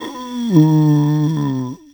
c_zombim4_hit2.wav